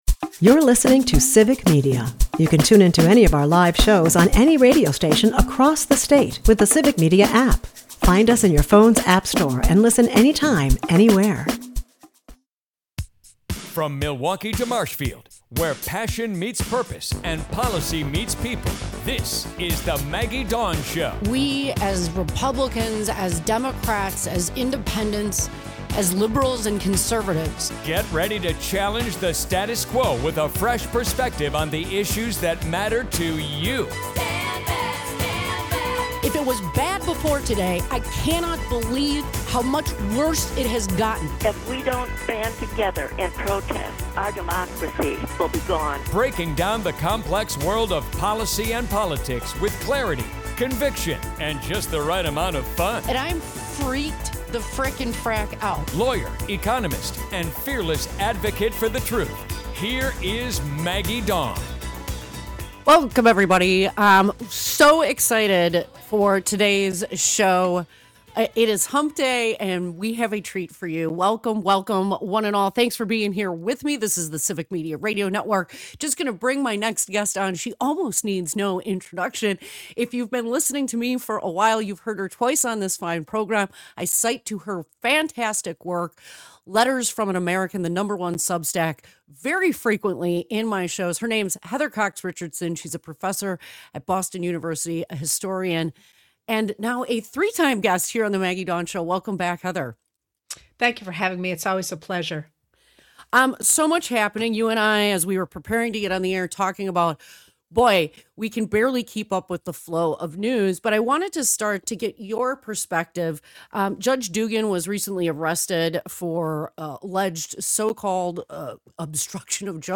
Guest: Heather Cox Richardson